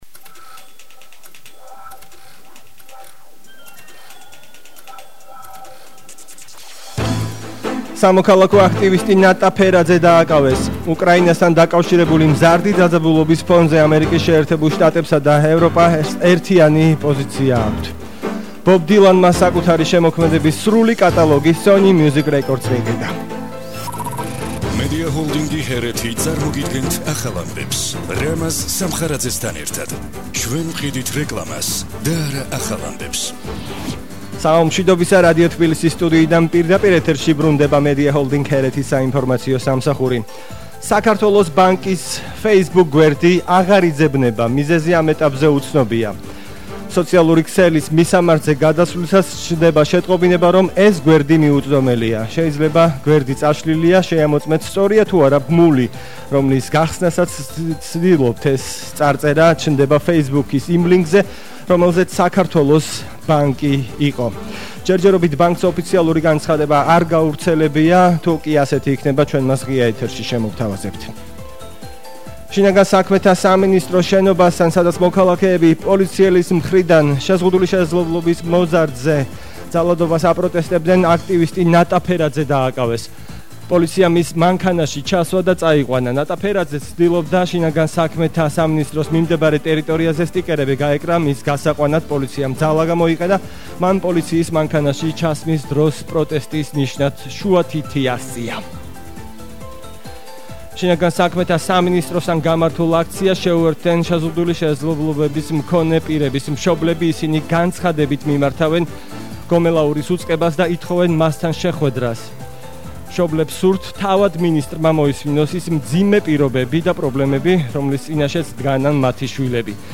ახალი ამბები 17:00 საათზე – 25/01/22